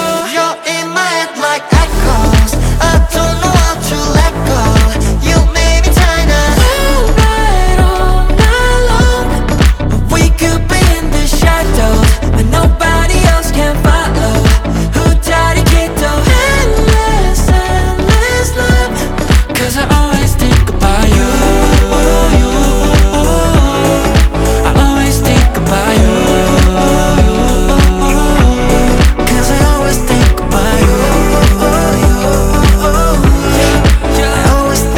K-Pop Pop
2025-07-28 Жанр: Поп музыка Длительность